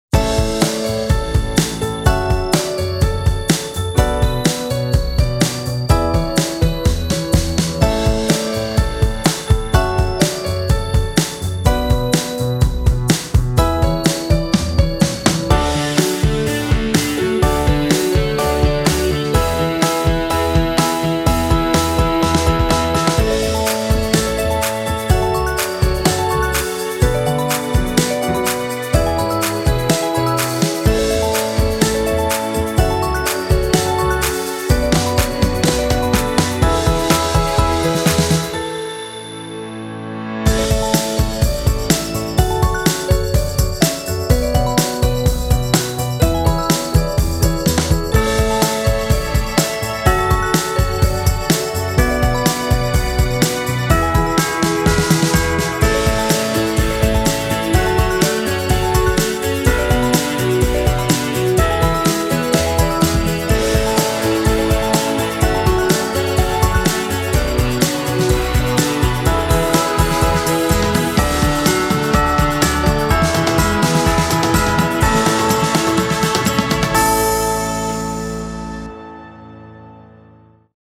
/ Pop / Rock